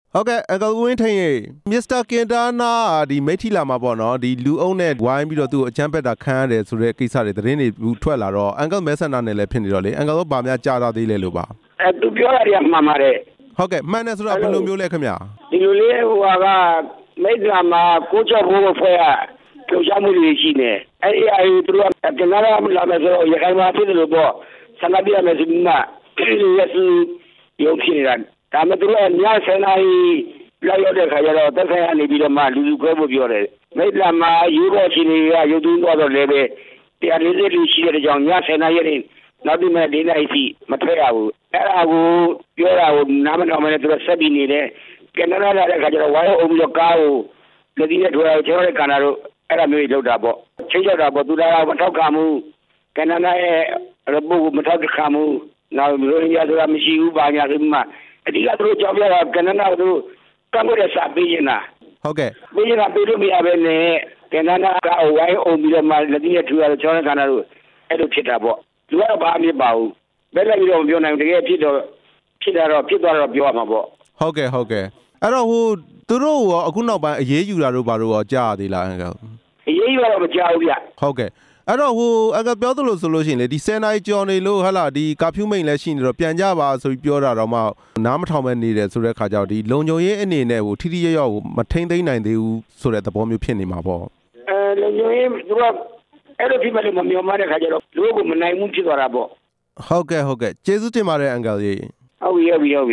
မိတ္ထီလာ ပြည်သူ့လွှတ်တော်ကိုယ်စားလှယ် ဦးဝင်းထိန်နဲ့ မေးမြန်းချက် နားထောင်ရန်